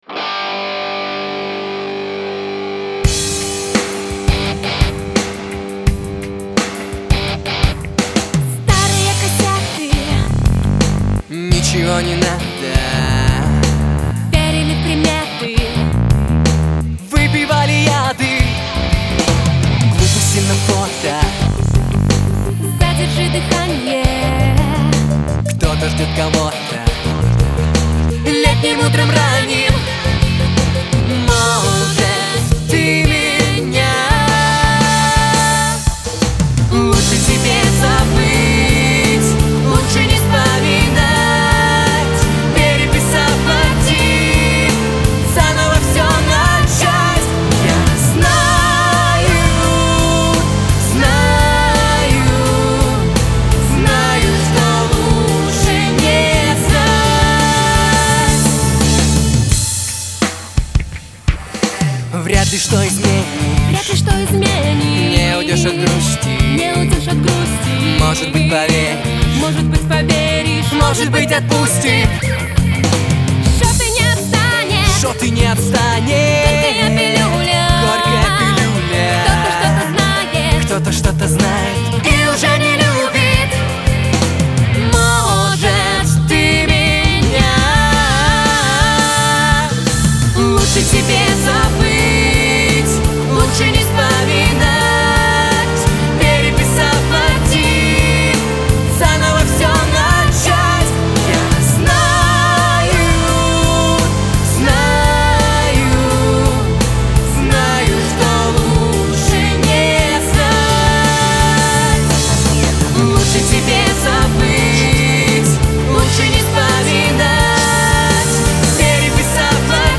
Послушайте - наша дуэтная работа
ты походу думаешь я не знаю сколько стоит сделать проф. запись? у тебя там проф. аранжировка. композиция. проф. запись. причесанный звук и поставленный вокал и теперь ты будешь меня лечить: что типа собрались с друзями за бутылочкой пивка за 500 руб сняли реп базу и записали на ноут????